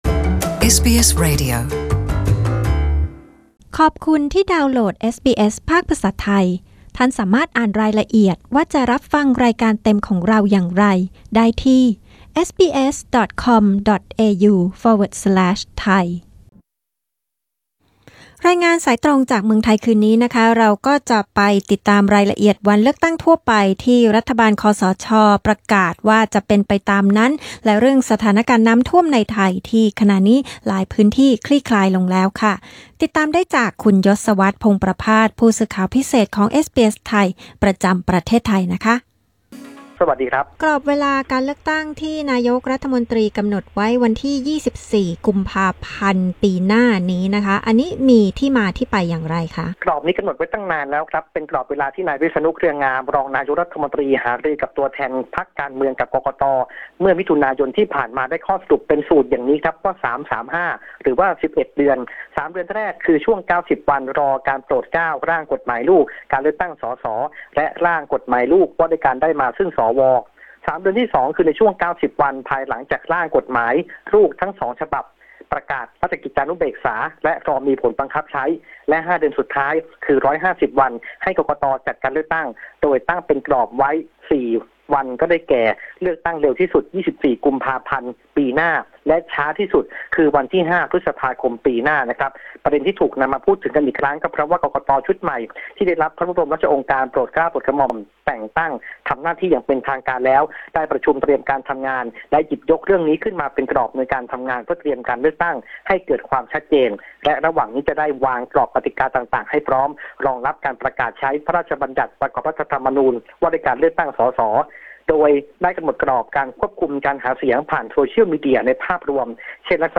Thai phone-in news 23 AUG 2018